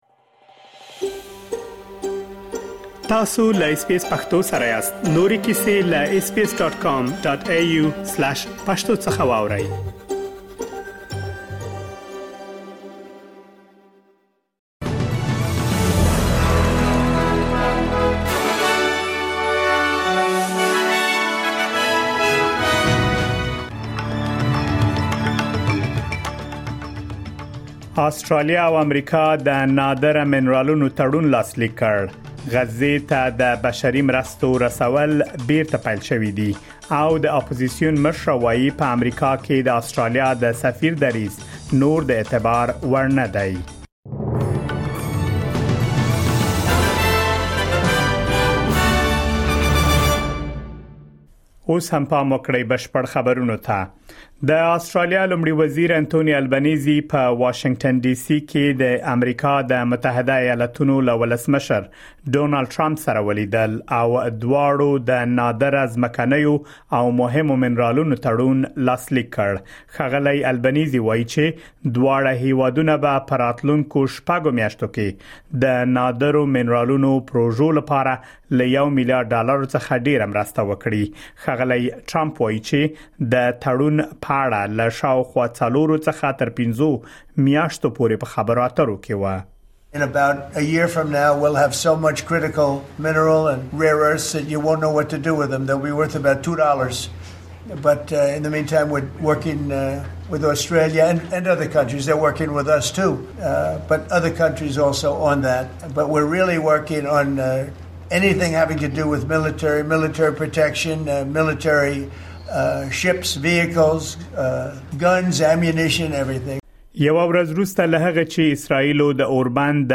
د اس بي اس پښتو د نن ورځې لنډ خبرونه دلته واورئ.